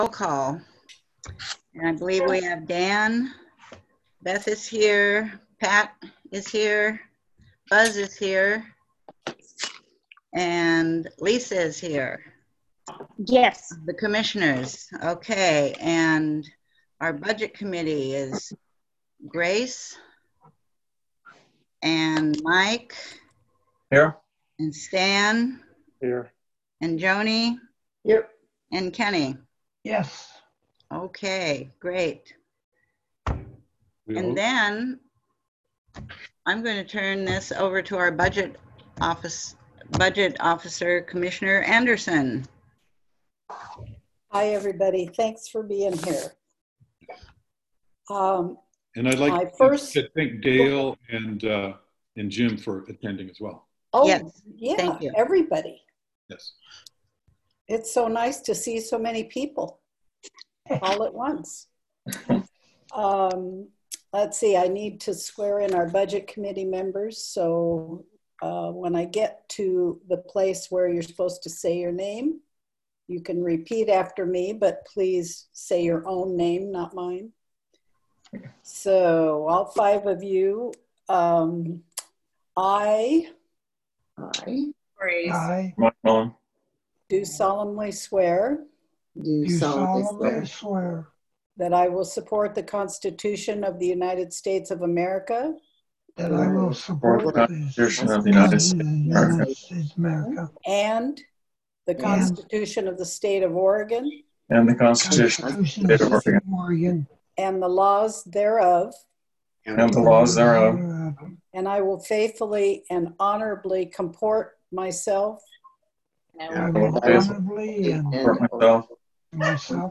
All district meetings are public meetings and interested persons are invited to attend.
Budget Committee Meeting